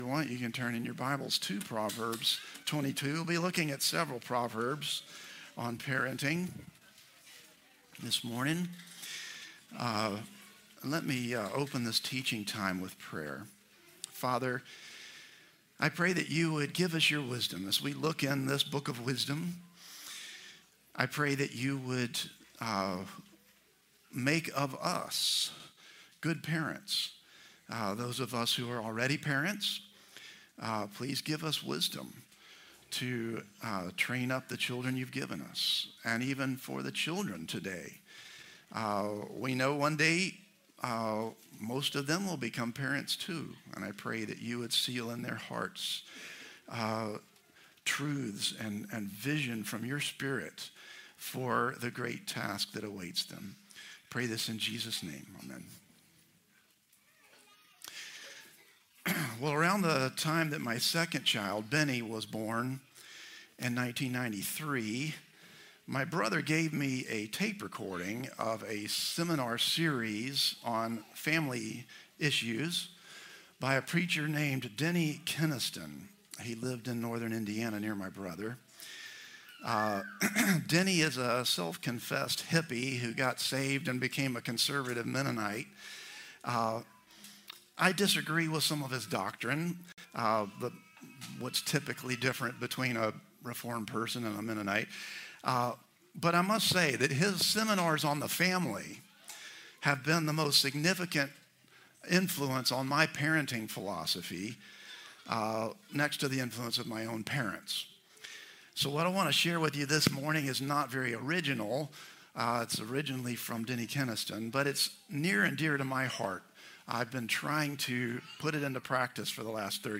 Christ the Redeemer Church | Sermon Categories Topical Sermons